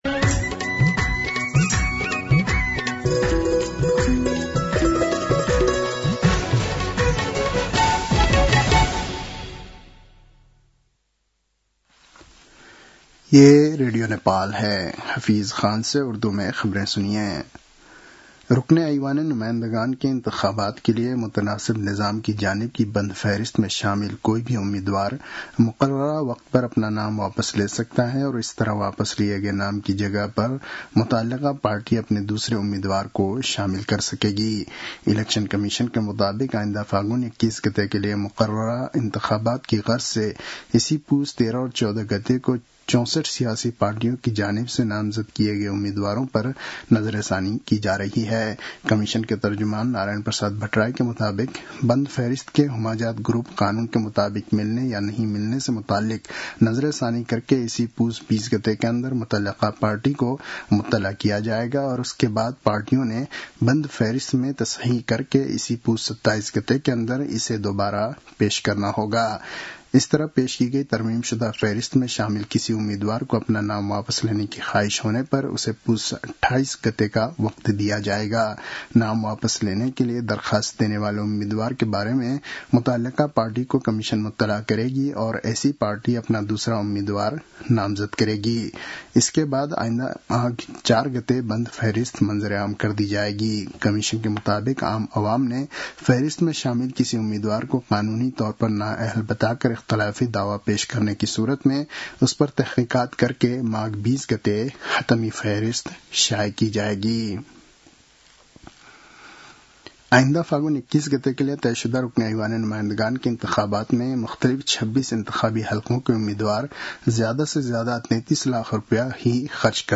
उर्दु भाषामा समाचार : १९ पुष , २०८२